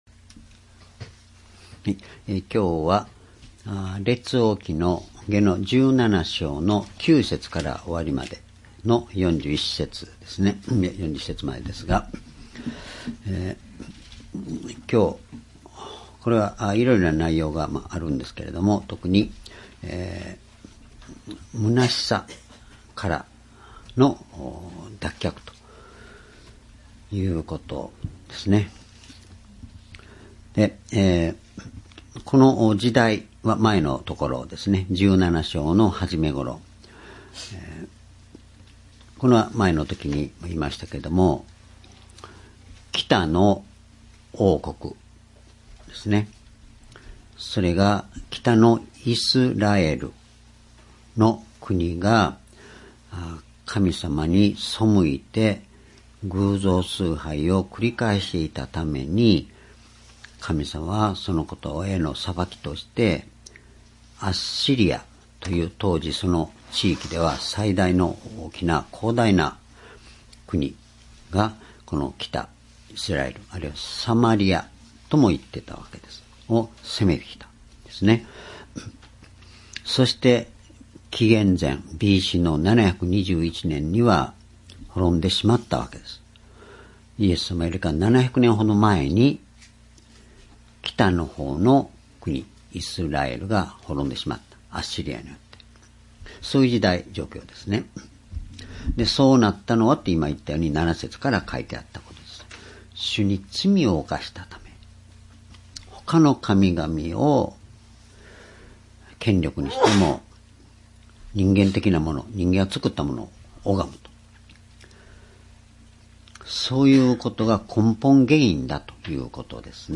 （主日・夕拝）礼拝日時 2019年12月1日 主日 聖書講話箇所 「むなしさからの脱出」 列王記下17章9節～41節 ※視聴できない場合は をクリックしてください。